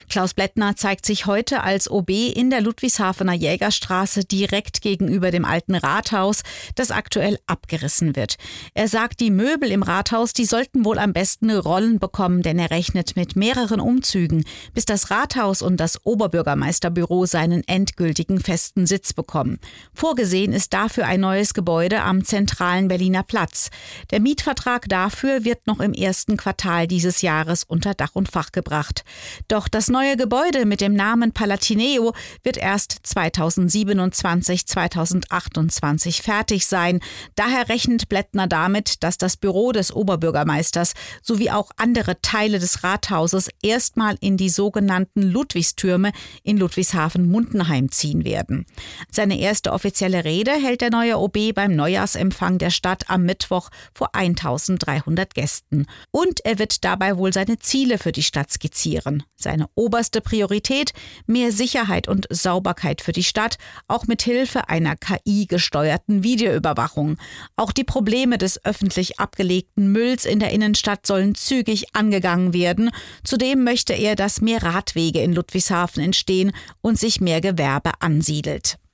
Ludwigshafeners neuer OB Klaus Blettner (CDU) beim Interview mit dem SWR im SWR Regionalstudio Mannheim-Ludwigshafen